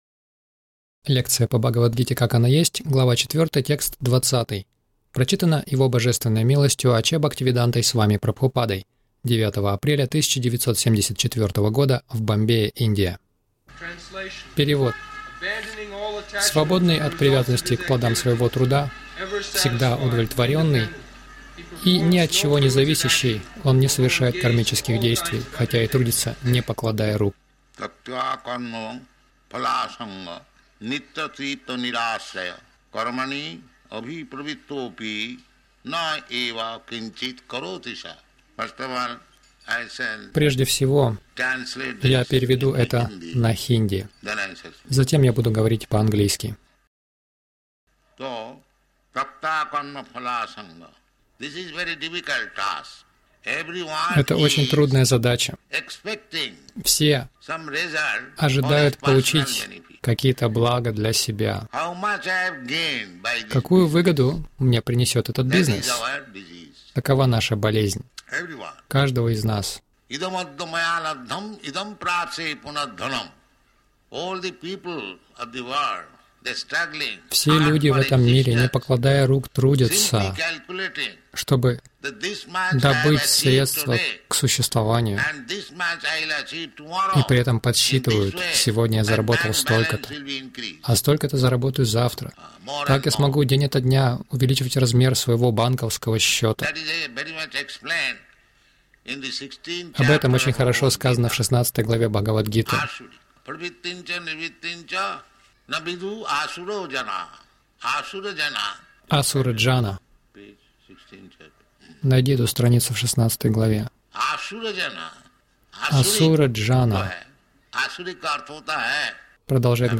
Милость Прабхупады Аудиолекции и книги 09.04.1974 Бхагавад Гита | Бомбей БГ 04.20 — Плоды нашей деятельности принадлежат Кришне Загрузка...